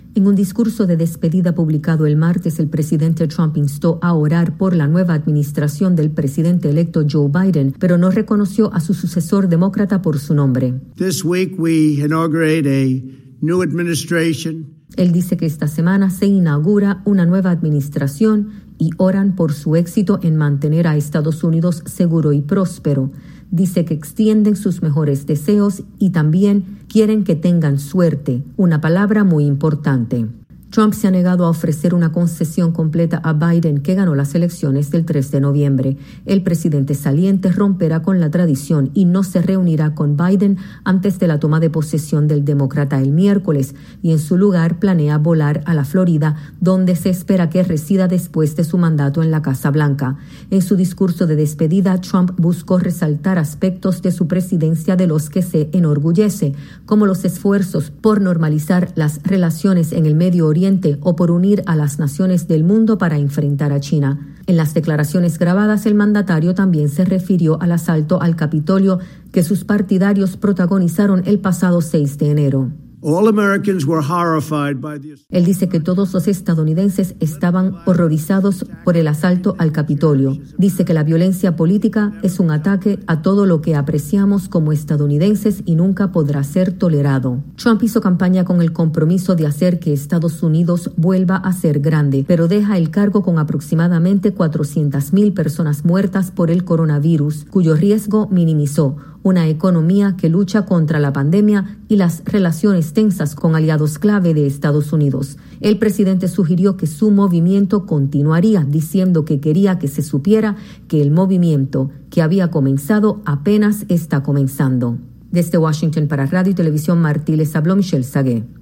El presidente Donald Trump pronuncia su discurso de despedida a pocas horas de concluir su mandato.